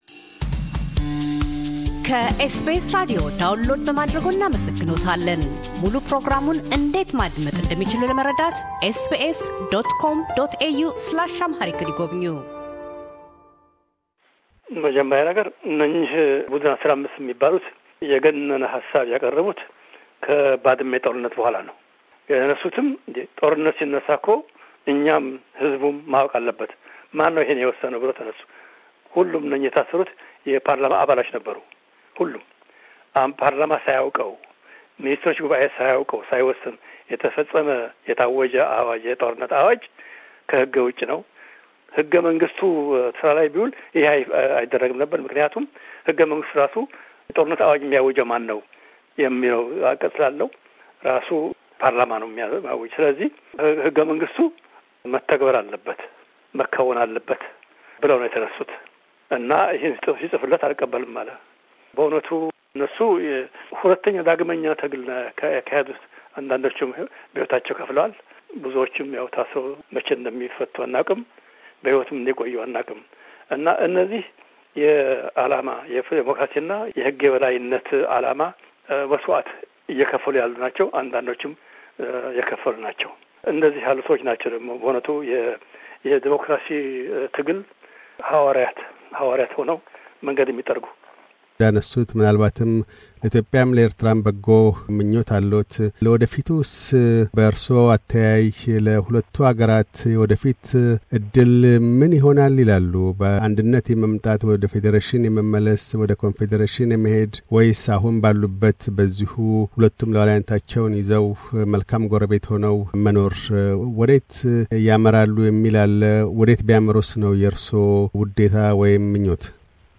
የመጀመሪያው አገር በቀል የኢትዮጵያ ጠቅላይ ዓቃቤ ሕግና ኋላም የኤርትራ ሕገ መንግሥት አርቃቂ ኮሚሽን ሊቀመንበር የነበሩት ፕሮፌሰር በረከት ሃብተስላሴ፤ በክፍል አምስት ቃለ ምልልሳችን የሕይወት ጉዞ ታሪካቸውን ያጠቃልላሉ። መንደርደሪያቸው በኢትዮጵያና በኤርትራ መካከል በባድመ ጦርነት መቀስቀስ ሳቢያ ‘ጦርነቱ ያለ ፓርላማና የሚኒስትሮች ምክር ቤት ይሁንታ እንደምን ታወጀ?’ ብለው የተቃውሞ ድምጻቸውን ካሰሙት ‘የቡድን ፲፭’ አባላት እንቅስቃሴ ነው።